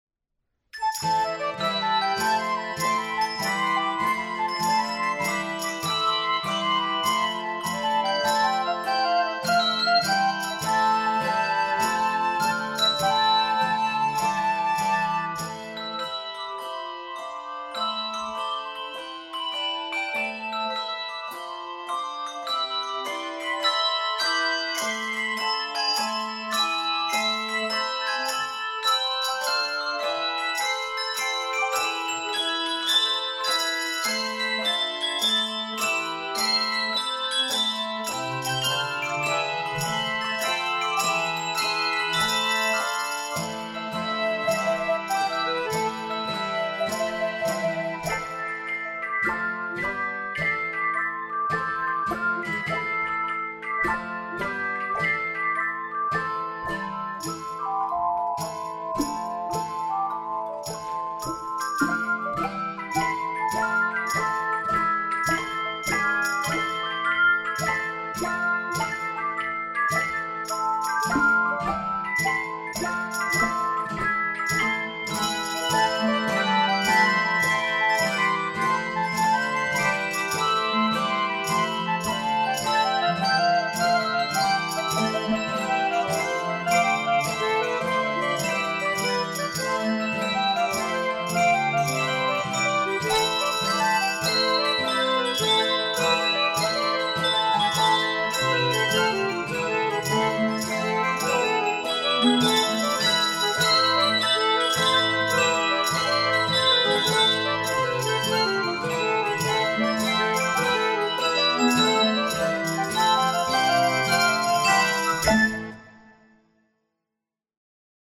Key of a minor.